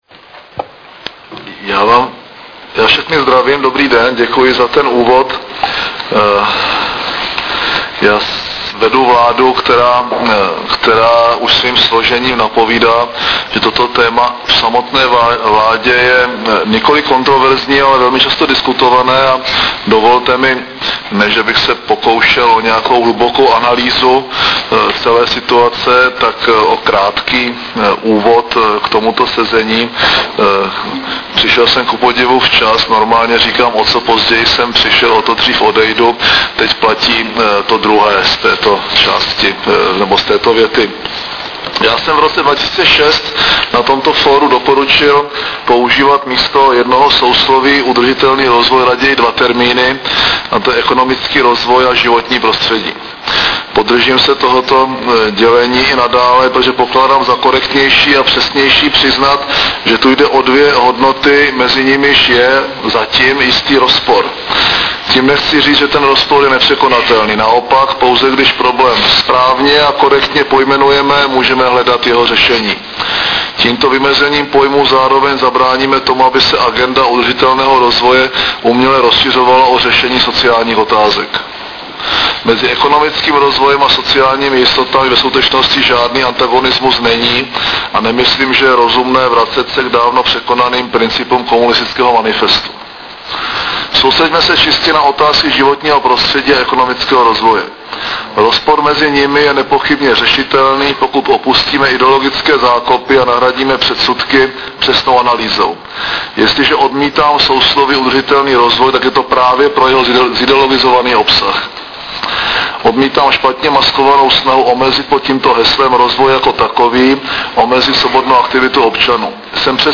Zvukový záznam projevu předsedy vlády Mirka Topolánka
Audiozáznam zahájení Fóra pro udrľitelný rozvoj 2008